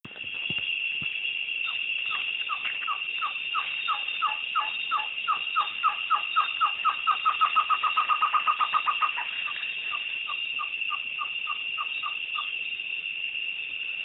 Trogon bairdii Baird's Trogon Trogón Vientribermejo
trogonbaird.wav